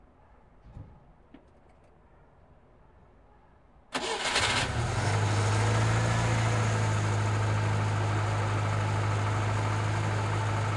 城市车辆 " 启动汽车Moskwich
我的同事试图启动它，结果成功了但电池组用完了，我的录音机也停了。
XYstereo
Tag: Moskvich-412 苏联 USSR 启动 Moskvich 汽车 开始-car 隆隆声 噪声